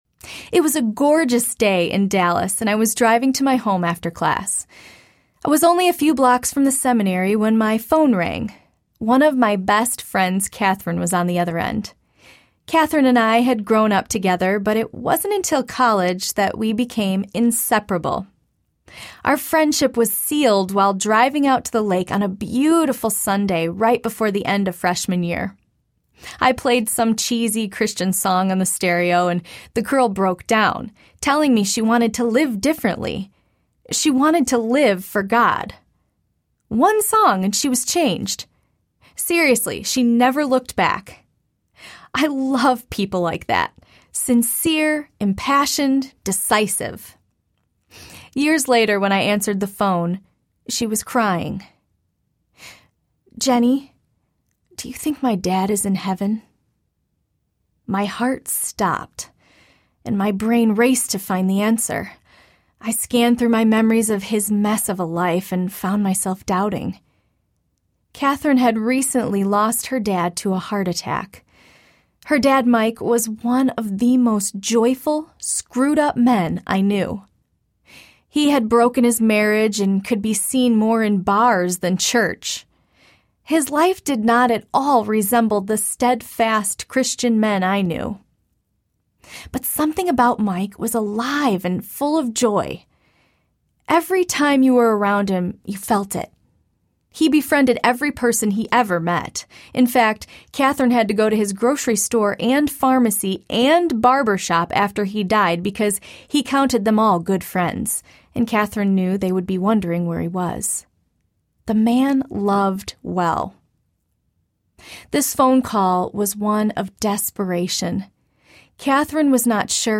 Anything Audiobook
6.0 Hrs. – Unabridged